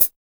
TOP PDL HH.wav